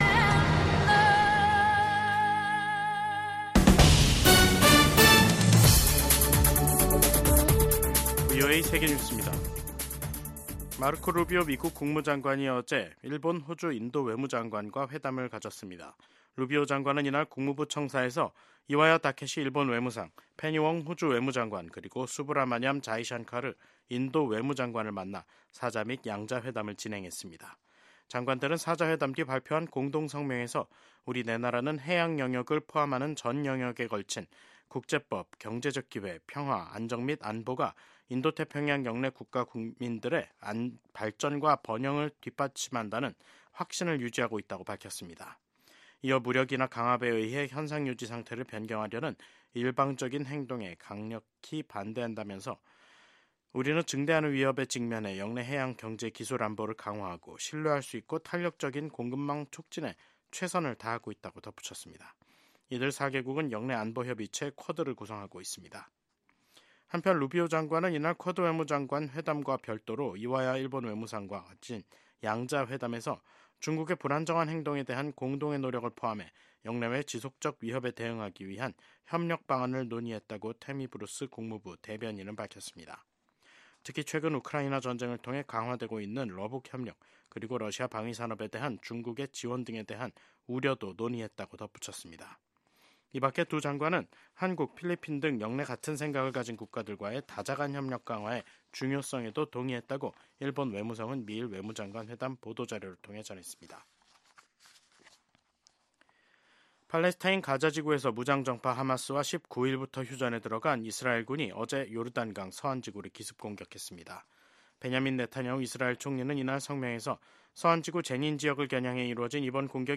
VOA 한국어 간판 뉴스 프로그램 '뉴스 투데이', 2025년 1월 22일 3부 방송입니다. 미국의 외교·안보 전문가들은 도널드 트럼프 대통령의 ‘북한 핵보유국’ 발언이 북한의 현실을 언급할 것일뿐 핵보유국 지위를 인정하는 것은 아니라고 분석했습니다.